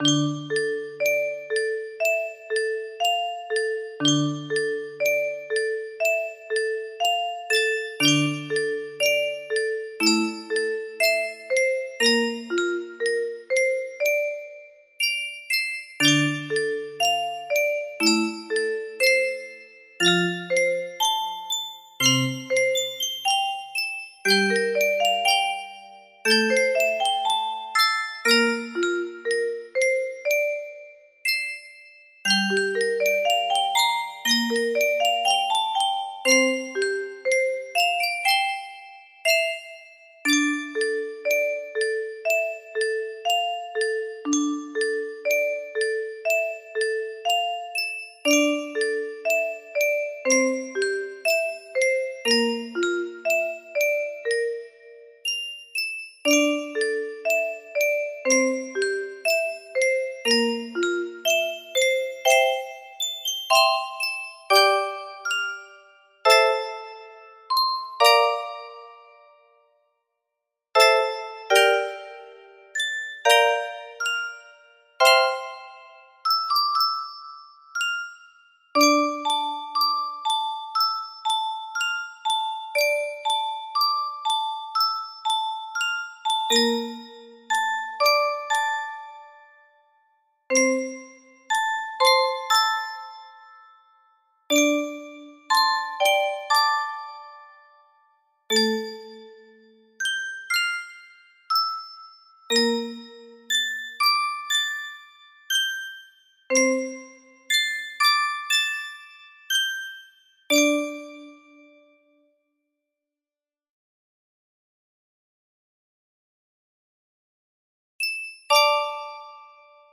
Going for a gothic sort of meloncholy, creeping melody here.